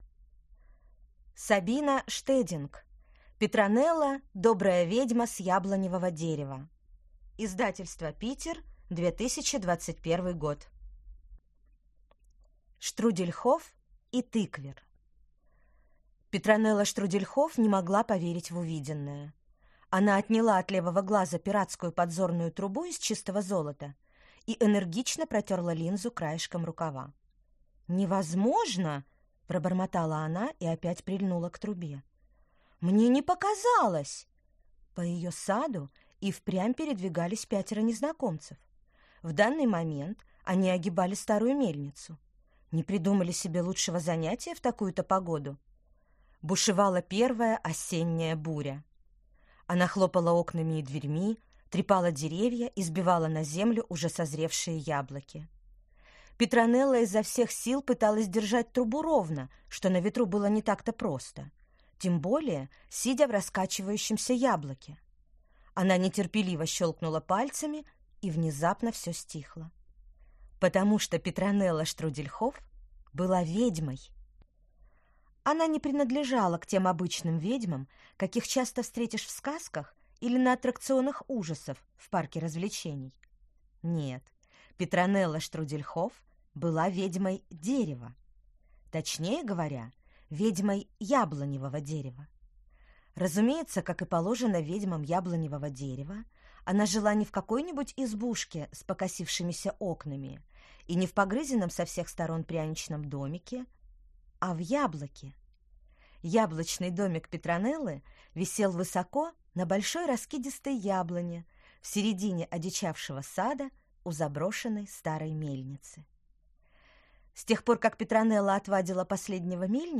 Аудиокнига Петронелла – добрая ведьма с яблоневого дерева | Библиотека аудиокниг